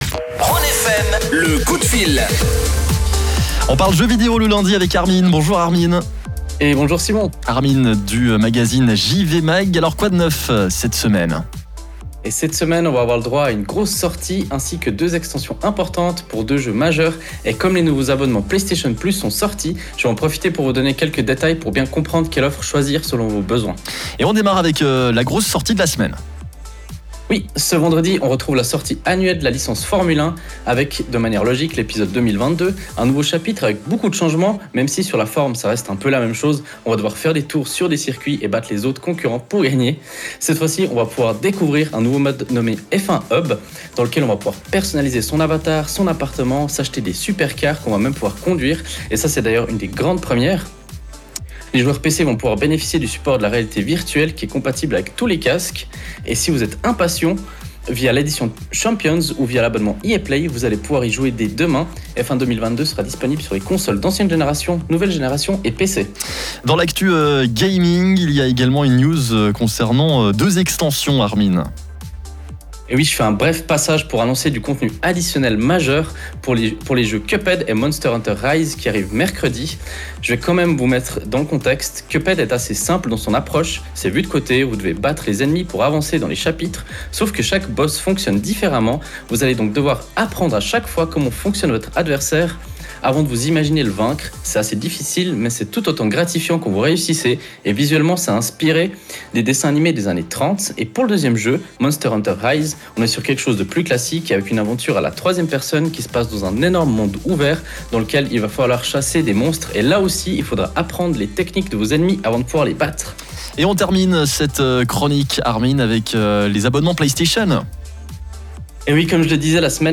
Le direct est à réécouter juste en dessus.